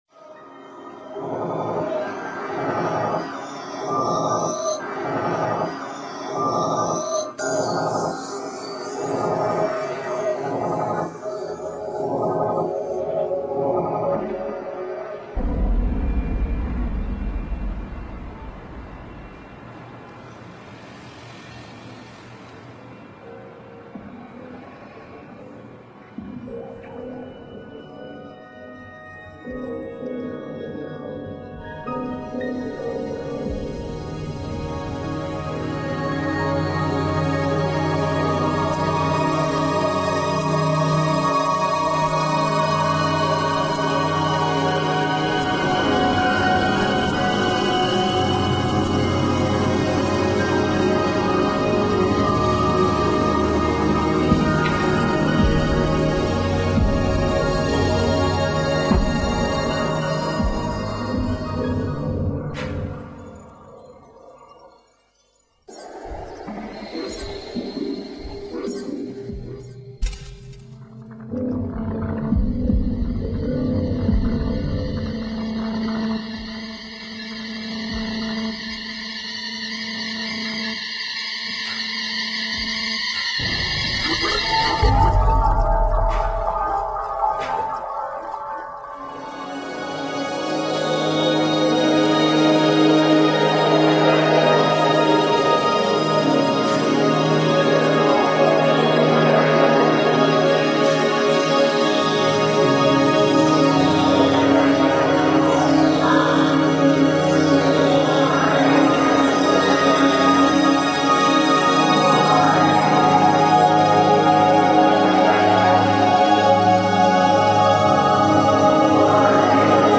All the sounds heard in the video were recorded from a live run of the system after several days of audience interaction. Notice the wide variety of sounds produced.
Figure 5: Video and sounds of the Eden Installation.